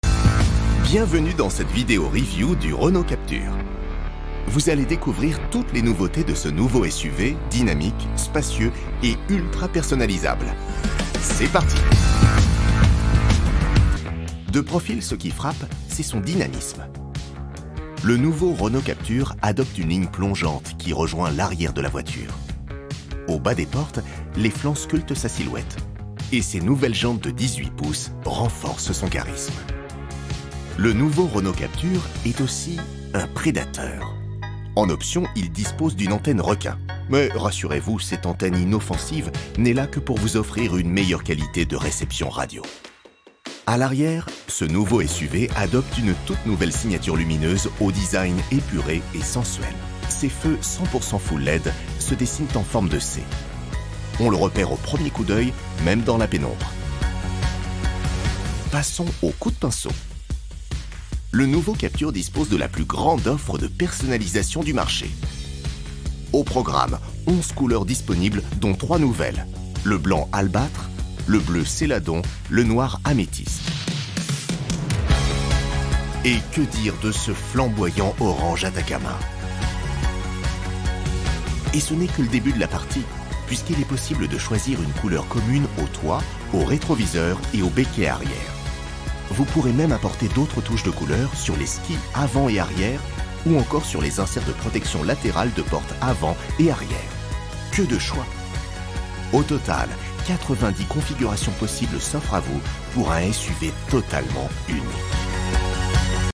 Voix-off naturelle, sympa et informative pour le nouveau Renault Captur
Sympa et naturel.
Enregistré chez Prodigious.
La hauteur de voix que j’ai utilisée pour ce projet était médium grave.
Ma voix a une certaine profondeur, un certain poids, qui donne de l’importance à ce que je dis.
Le ton de ma voix était naturel, sympa, informatif et amusé.